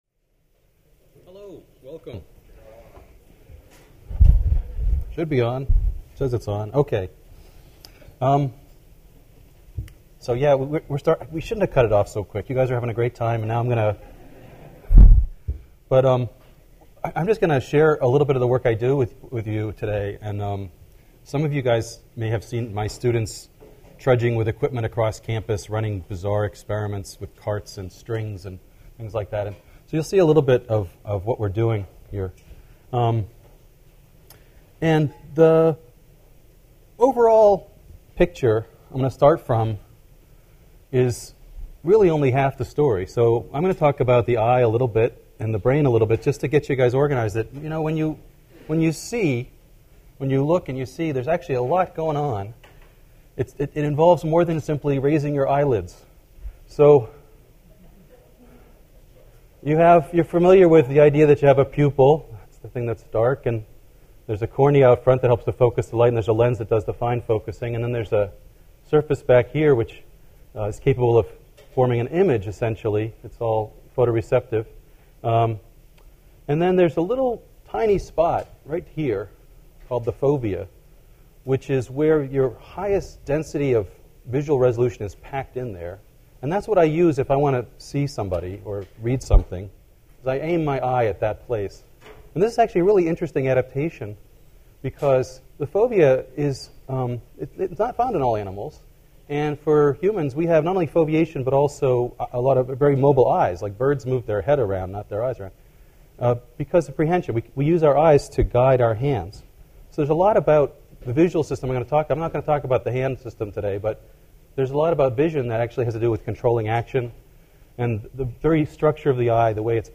speaks at February's installment of the Second Tuesday Science Cafe.
speaks about perception in this installment of the Second Tuesday Science Cafe lecture series.